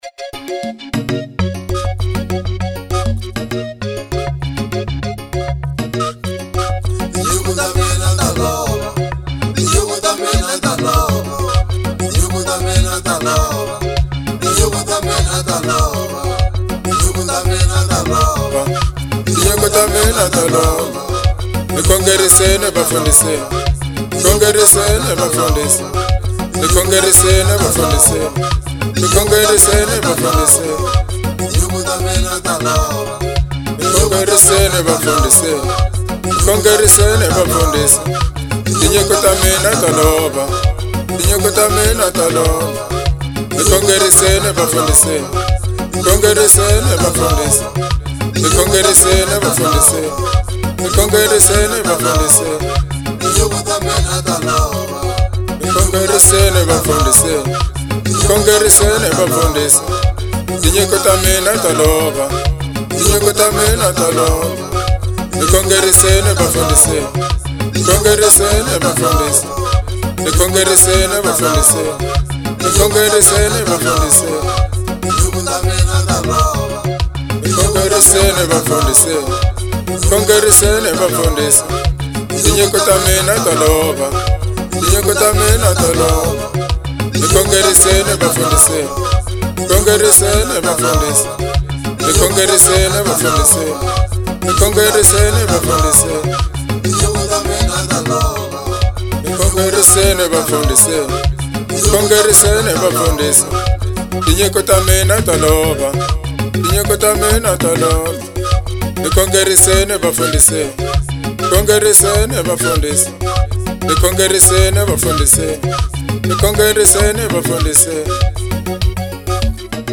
07:06 Genre : Gospel Size